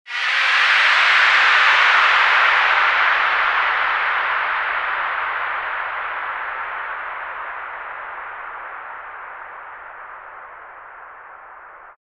FX-703-WHOOSH
FX-703-WHOOSH.mp3